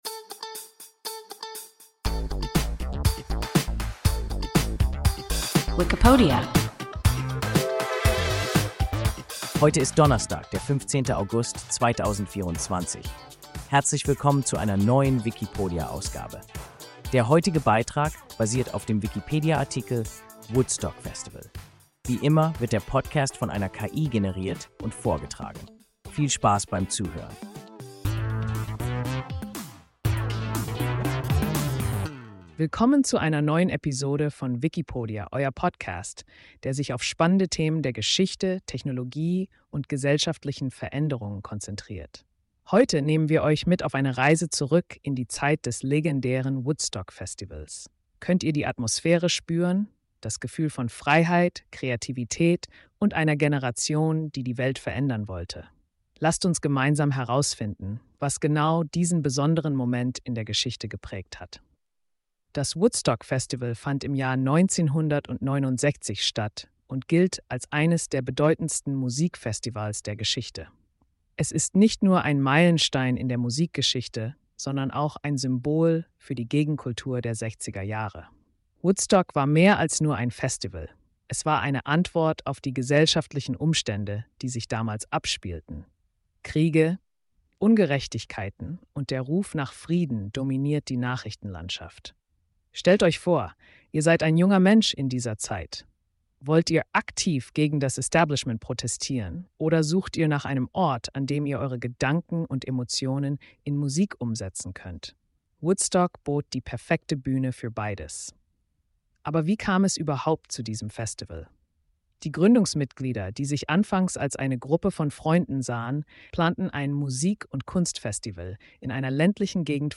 Woodstock-Festival – WIKIPODIA – ein KI Podcast